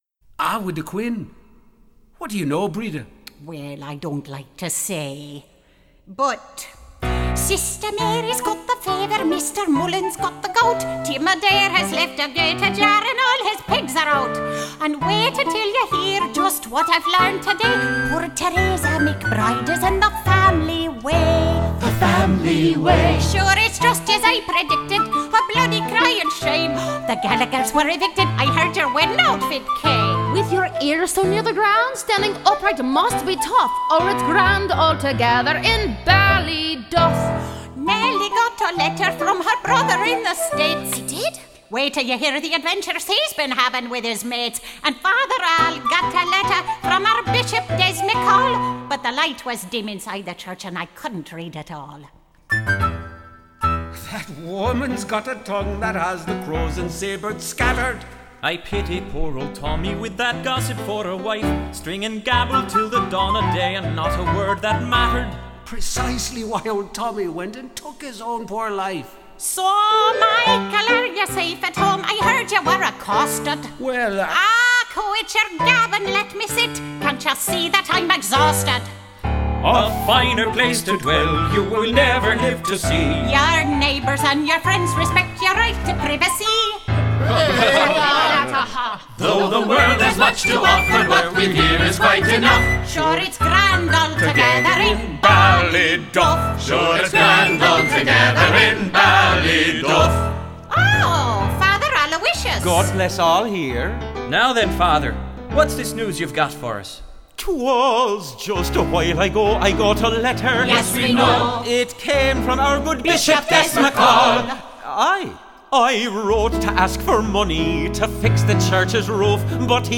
- An Irish Musical Comedy